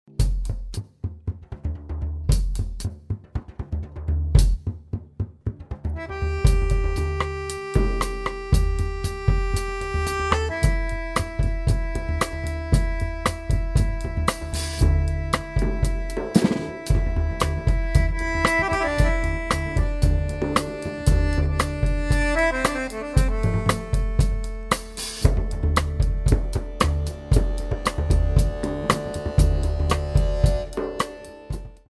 bodhran, drum set
accordion, harmonium